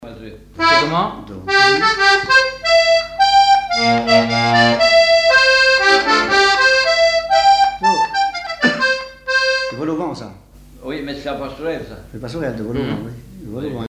instrumental
danse : quadrille : pastourelle
Pièce musicale inédite